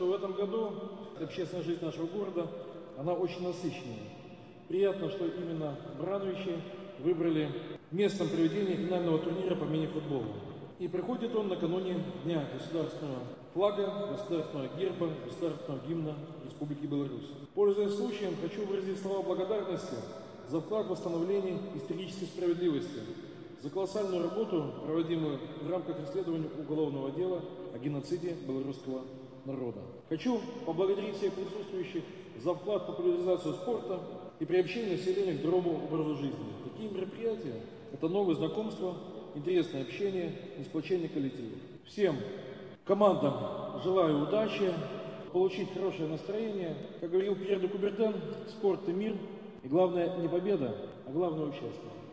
Такие встречи помогают сплотить коллектив, а также играют немаловажную роль в формировании здорового образа жизни. Спортсмены показали, что находятся в хорошей физической форме и прекрасно владеют навыками игры в мини-футбол, — отметил глава города Михаил Баценко.